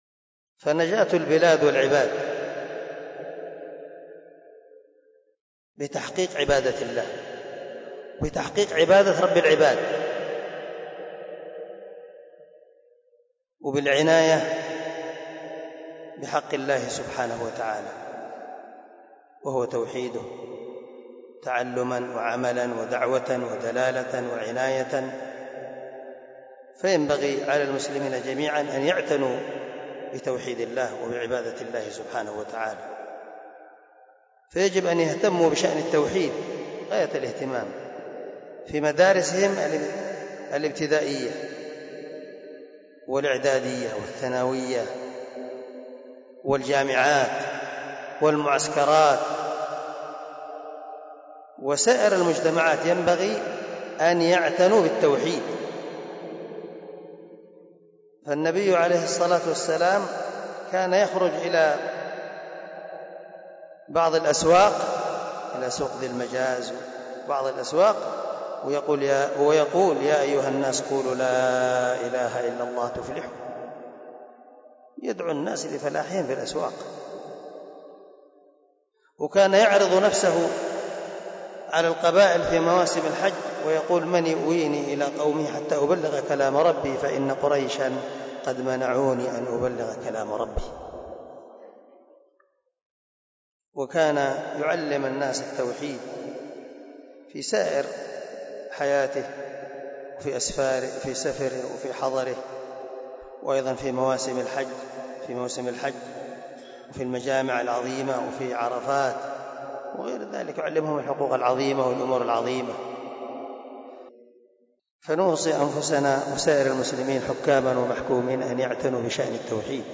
مقطع مقتبس من درس في البخاري